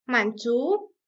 mǎnzú - mản chú Thỏa mãn